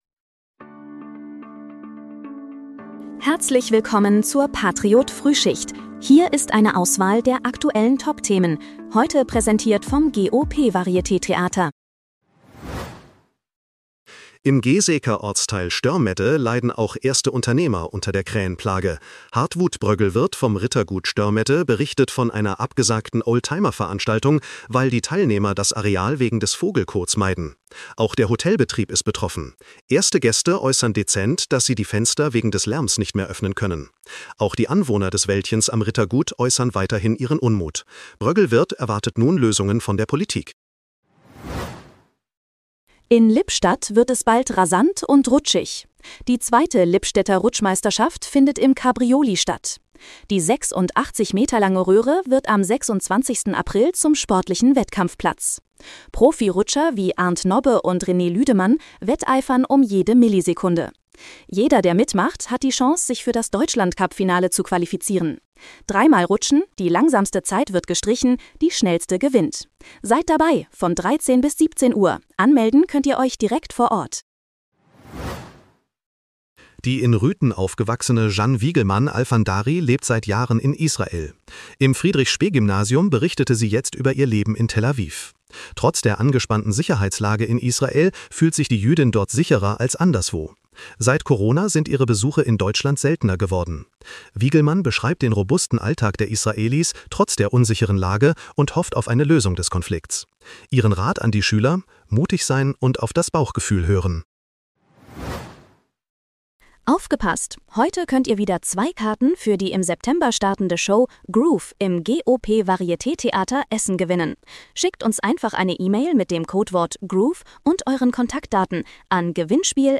Dein morgendliches News-Update
mit Hilfe von Künstlicher Intelligenz.